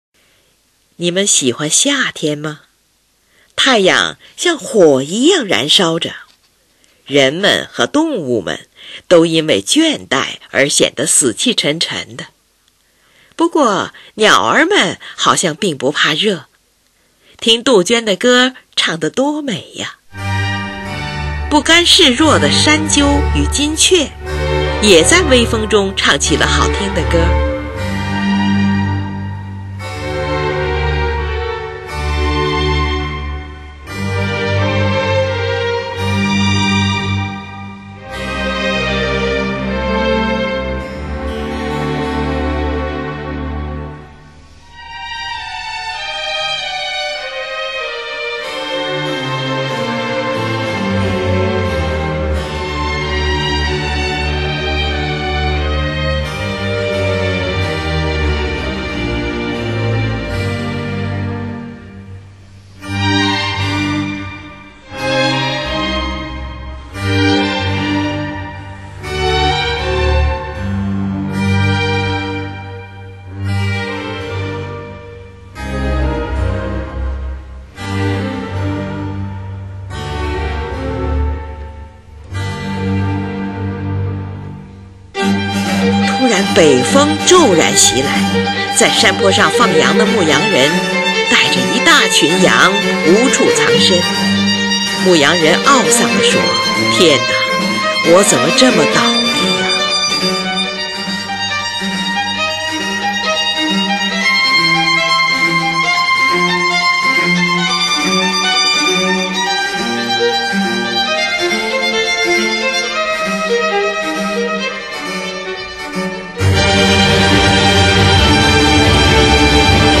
夏天--g小调
开头合奏表现“太阳像火一样地燃烧，人和动物都因倦怠而显得死气沉沉”。
第一次主奏表现杜鹃啼叫。
第二次主奏表现山鸠与金雀的歌唱与微风轻拂。
第三次主奏表现牧羊人为骤雨征服，为自己的倒运而哭泣。